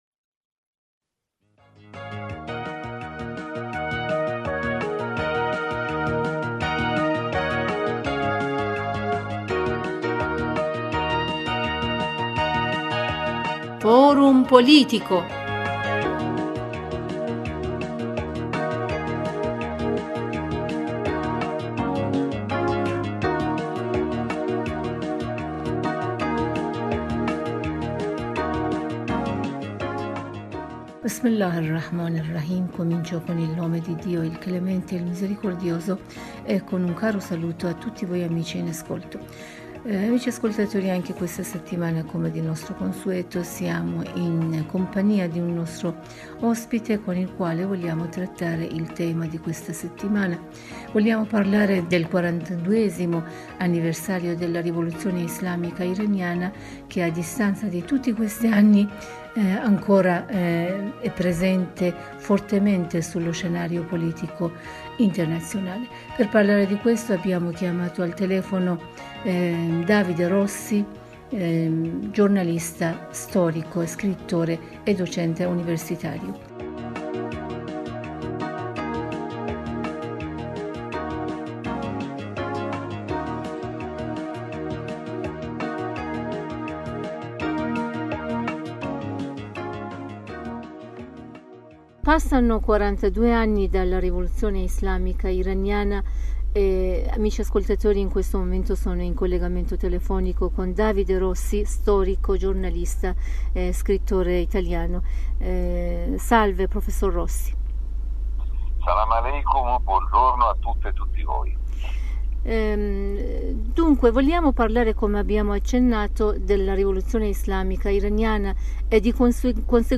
storico e docente universitario in un collegamento telefonico con la Radio Italia della Voce della Repubblica islamica dell'Iran...